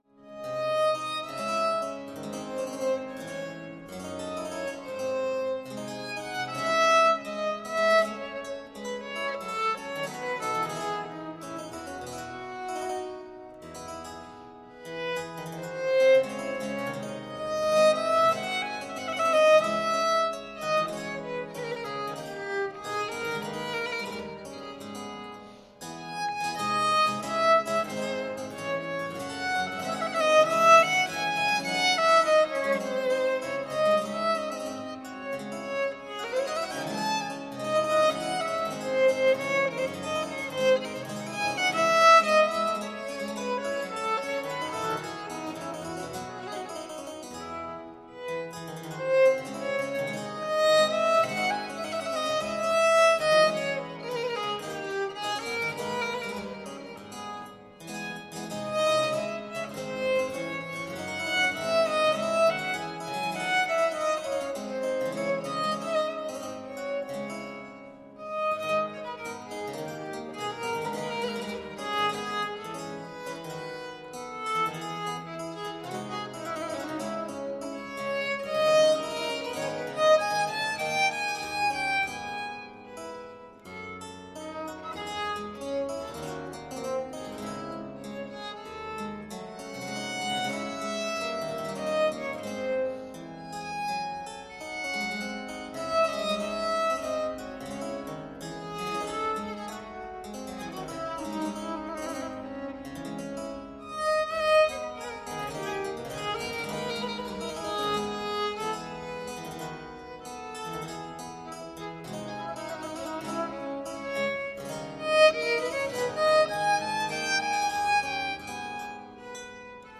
St Vedast Foster Lane